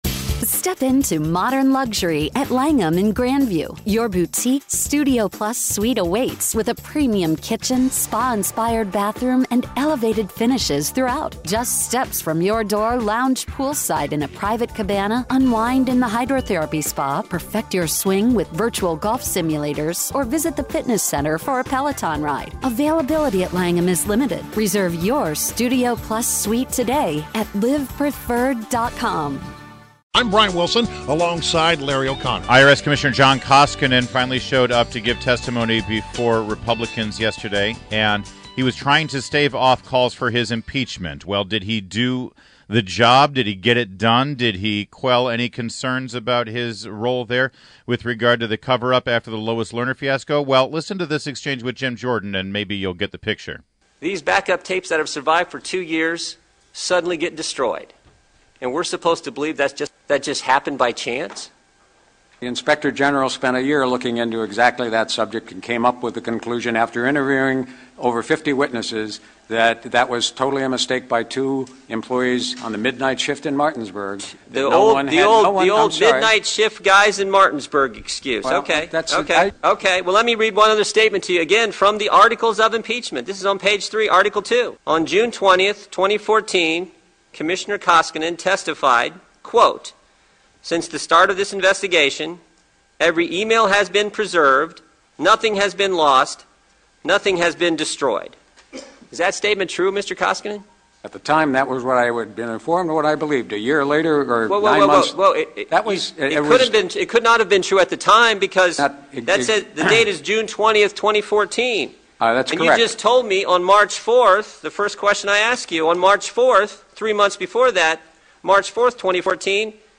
INTERVIEW – REP. JIM JORDAN — (R-Ohio), Chairman of the House Freedom Caucus and